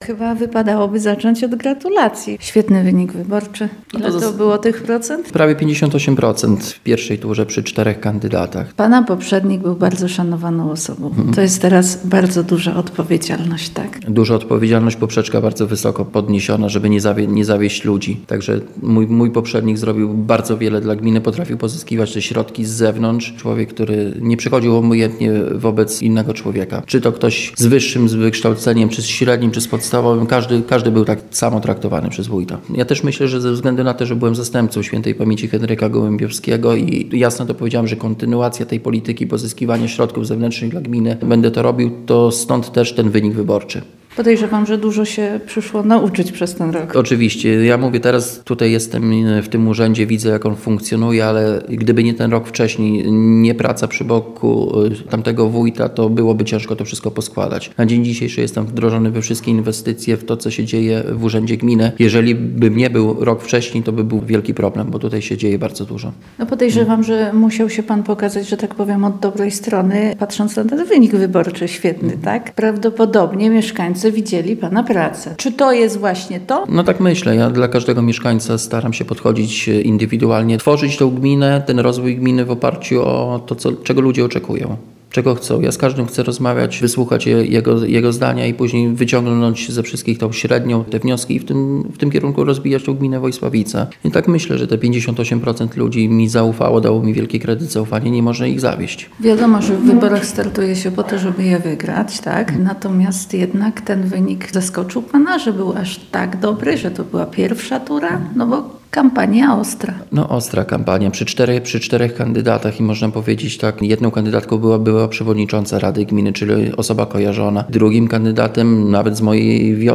Rozmowa z wójtem Wojsławic Bartłomiejem Szajnerem